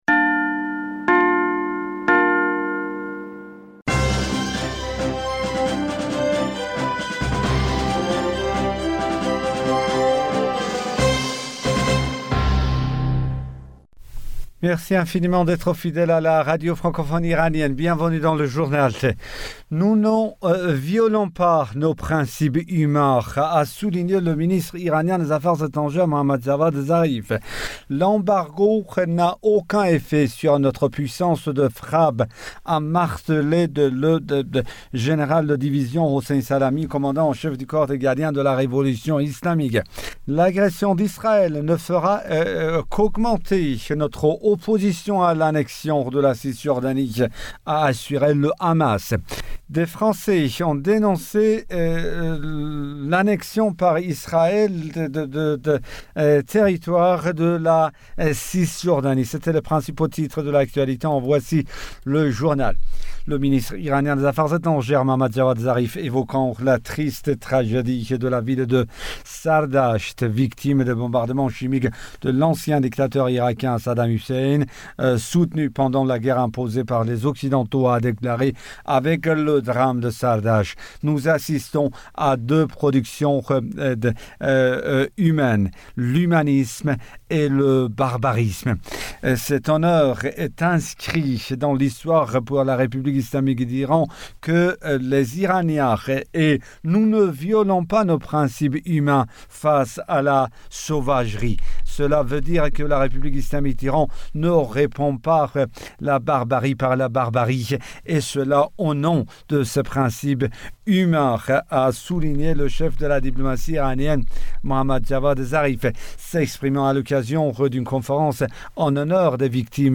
bulletin d'information